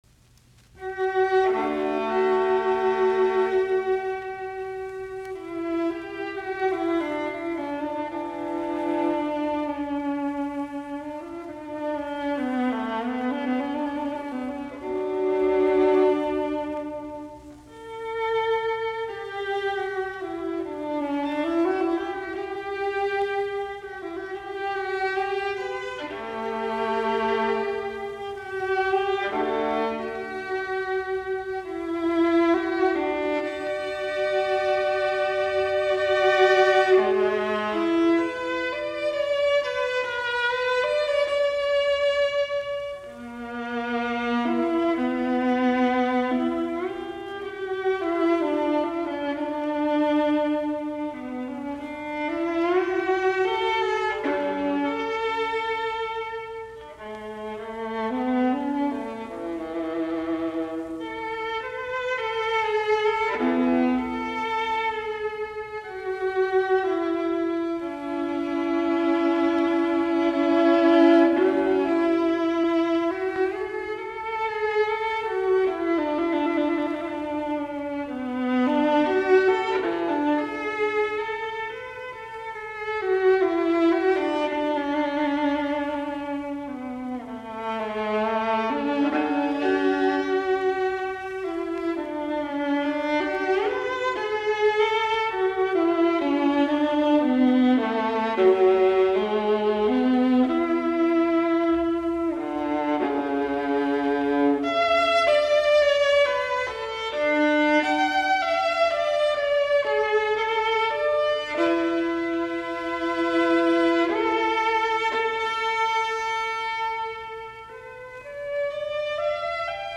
alttoviulu
Soitinnus: Alttoviulu.